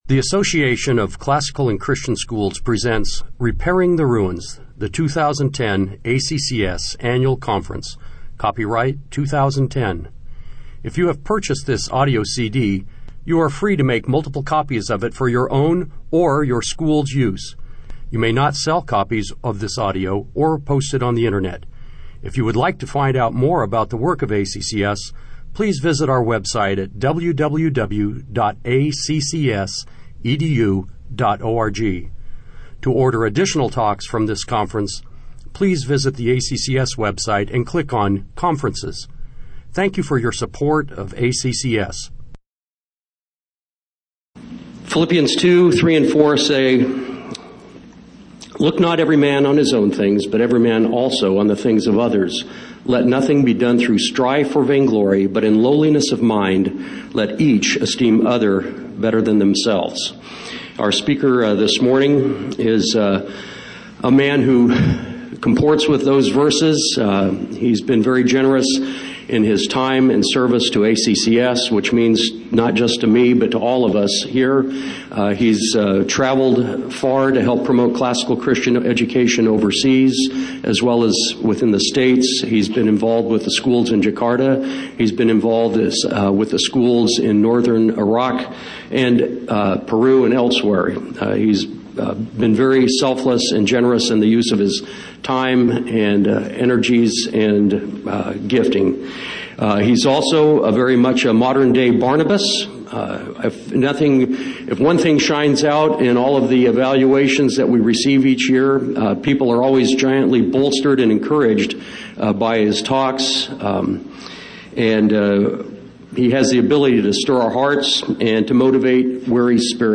2010 Plenary Talk | 0:47:08 | All Grade Levels, Leadership & Strategic